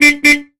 Car Horn: Antique, Various.